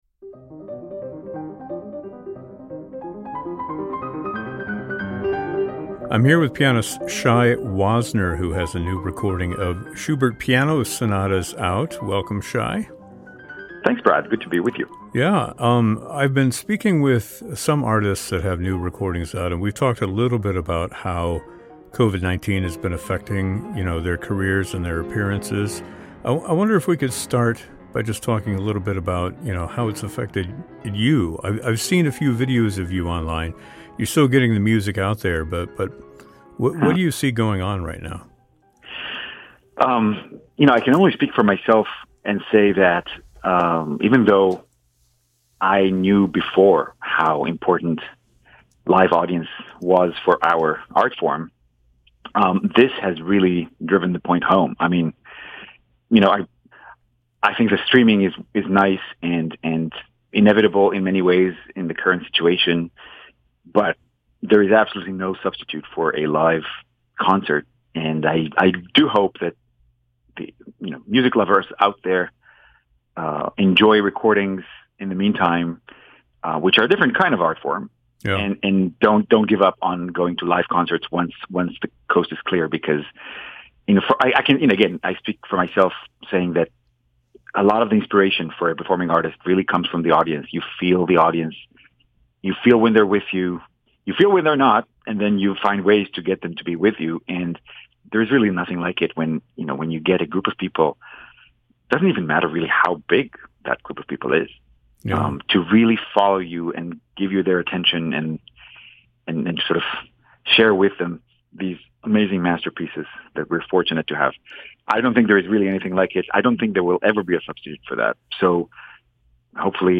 In this conversation, Shai reflects on the sonatas found in this album and how they connect to the current situation, as the performing arts community struggles with the isolation imposed by the global coronavirus pandemic.